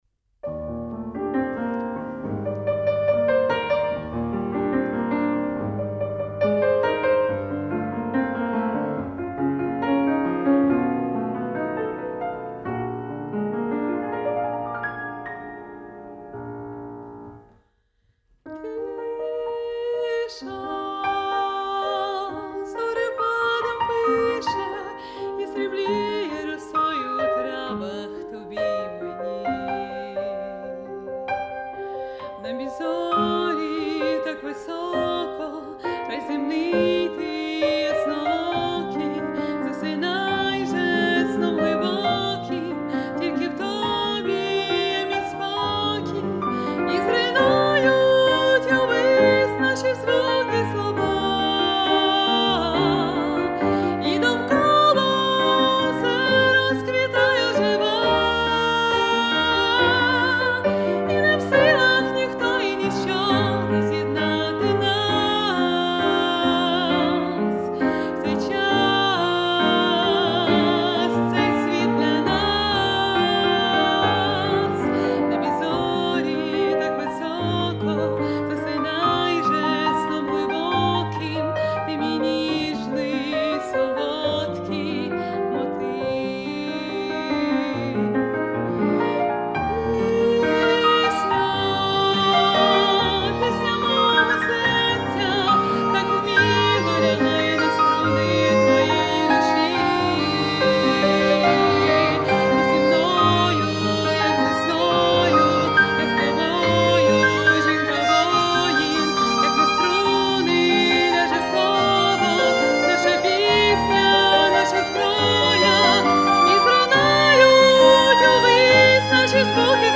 Рубрика: Поезія, Авторська пісня
Гарна пісня! 12 Тільки виконання, на мою думку, мало би бути більш тихим, ніжним.